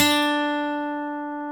Index of /90_sSampleCDs/Roland L-CDX-01/GTR_Steel String/GTR_ 6 String
GTR 6-STR30Y.wav